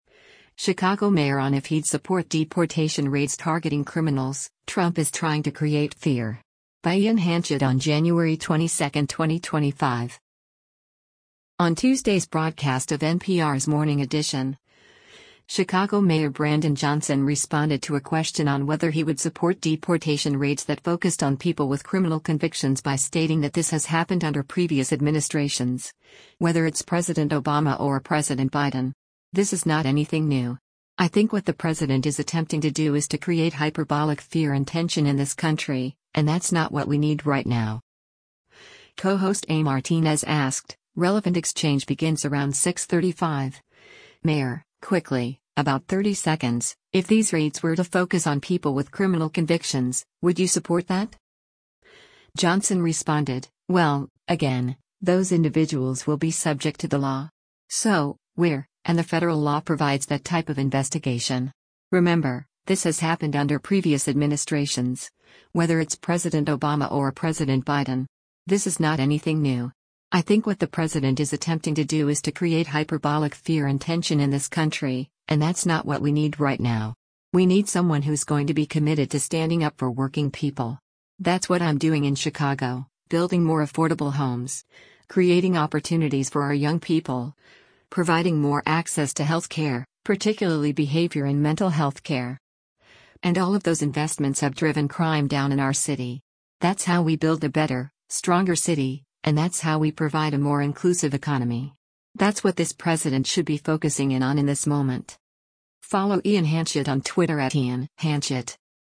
Co-host A Martínez asked, [relevant exchange begins around 6:35] “Mayor, quickly, about 30 seconds, if these raids were to focus on people with criminal convictions, would you support that?”